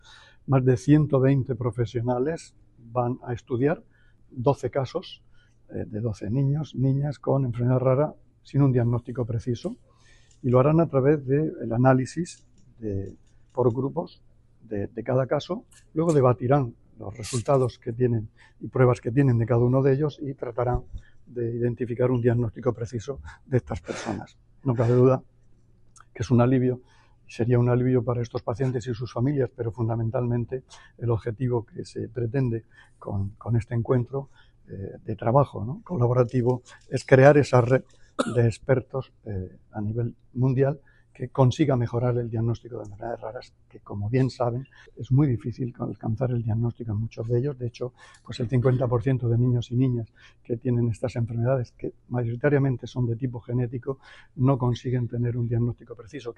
Sonido/ Declaraciones del consejero de Salud sobre el `Hackathon de enfermedades raras no diagnosticadas¿ que se celebra en la Región de Murcia.